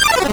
snd_soul_battle_start.wav